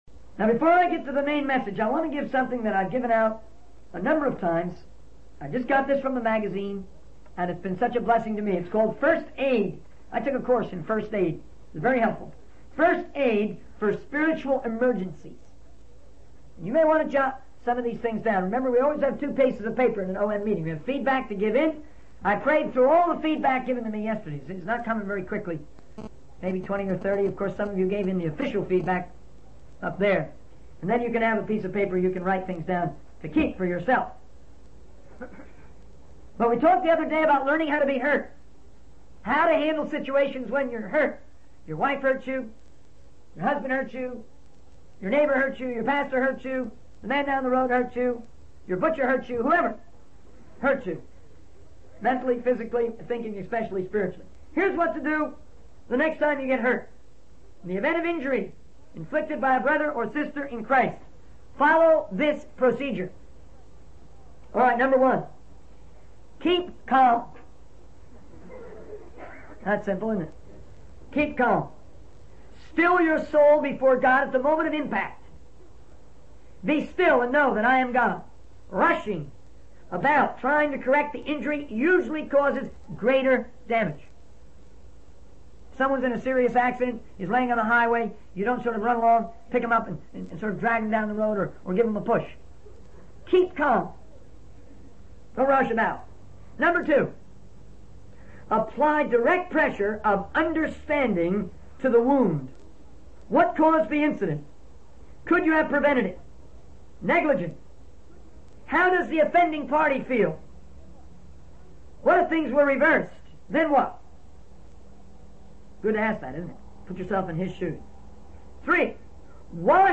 In this sermon, the preacher emphasizes the importance of not wasting time and using it wisely.